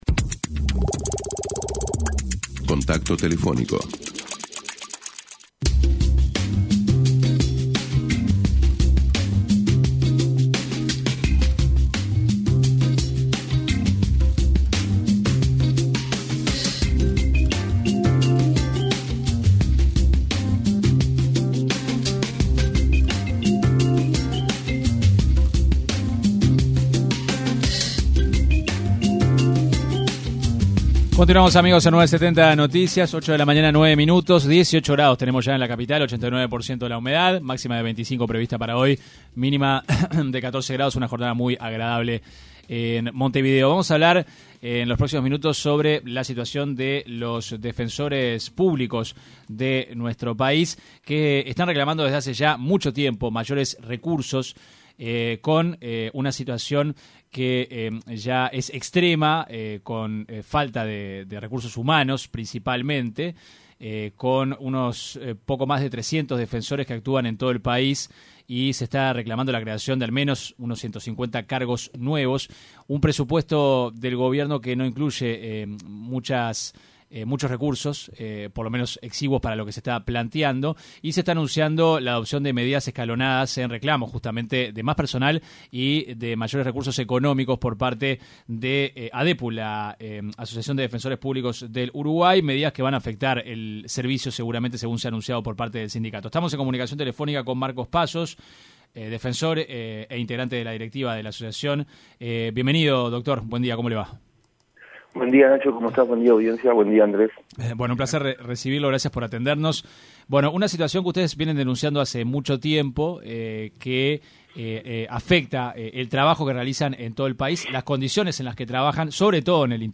una entrevista con 970 Noticias